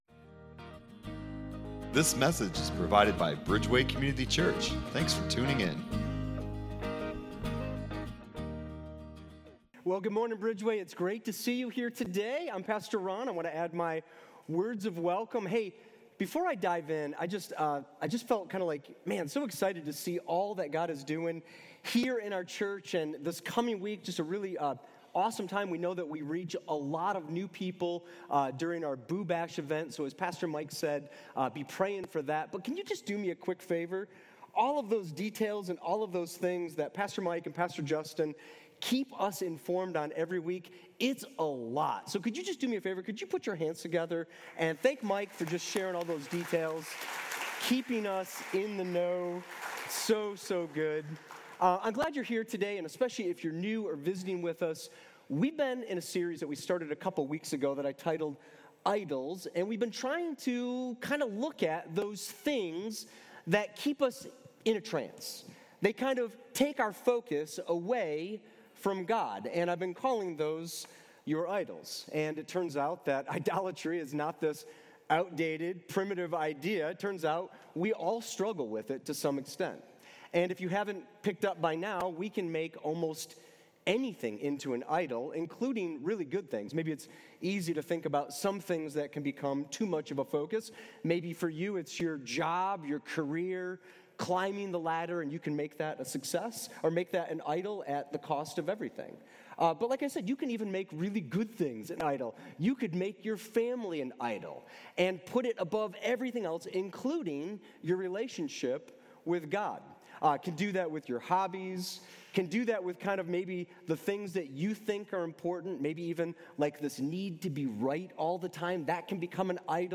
Download Sermon Discussion Guide